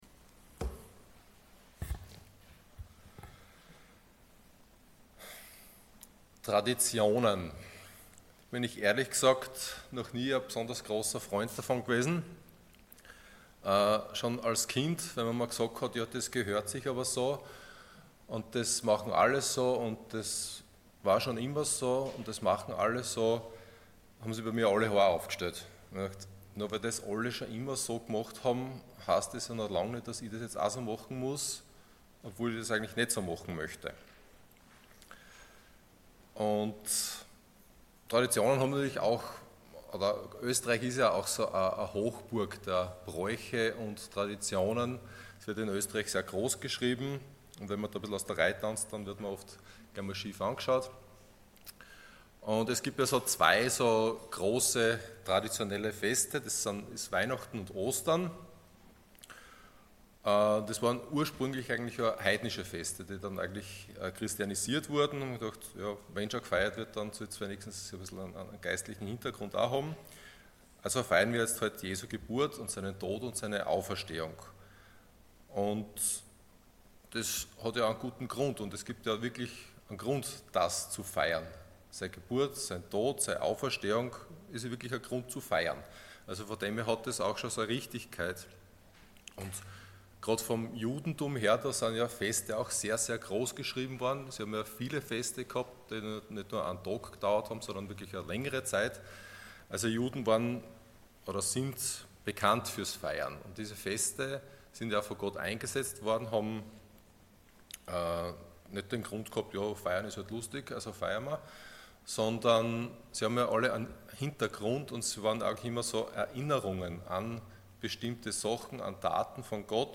10.11.2024 Erinnerung Prediger